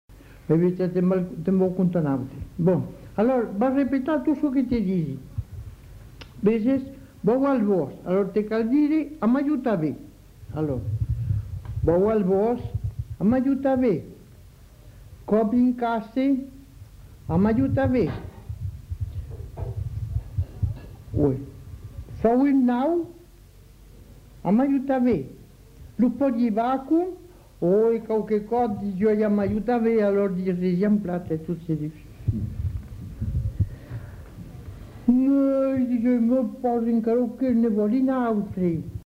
Aire culturelle : Haut-Agenais
Genre : conte-légende-récit
Effectif : 1
Type de voix : voix de femme
Production du son : récité
Classification : contes-attrape